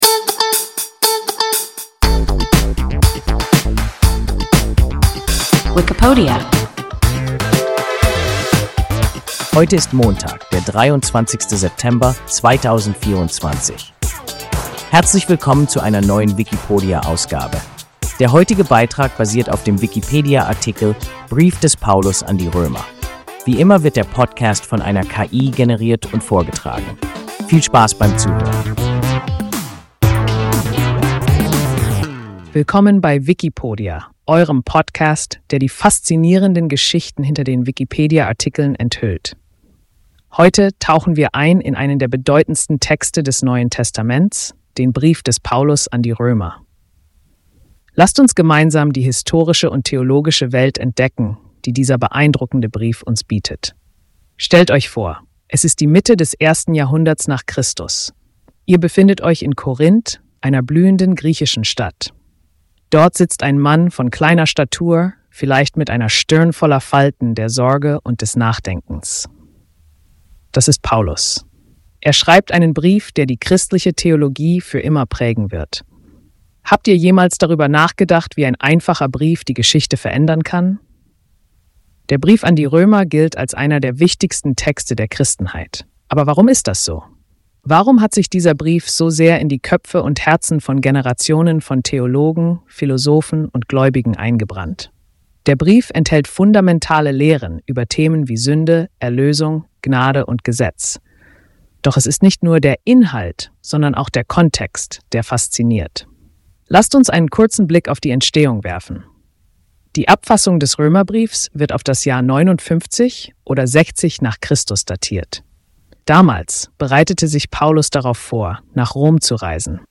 Brief des Paulus an die Römer – WIKIPODIA – ein KI Podcast